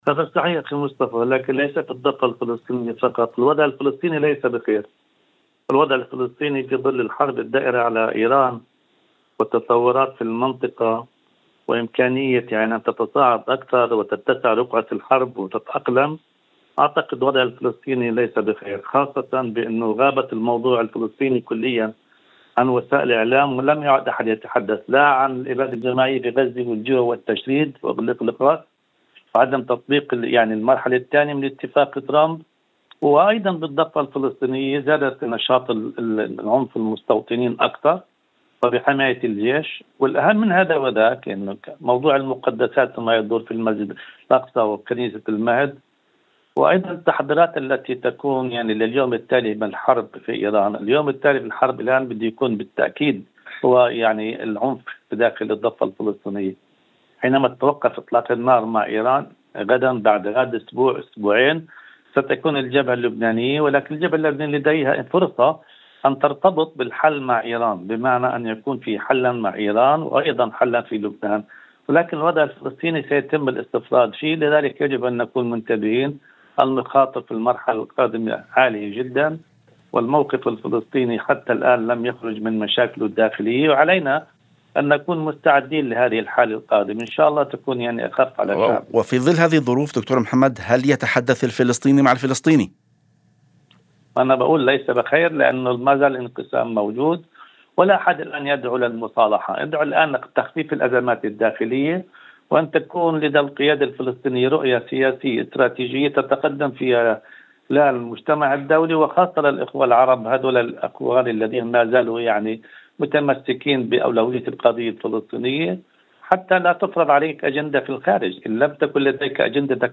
وأضاف في مداخلة هاتفية ضمن برنامج "يوم جديد": "لم يعد أحد يتحدث عن الإبادة في غزة أو التجويع أو التهجير.. وهناك تراجع في الاهتمام بما يجري على الأرض."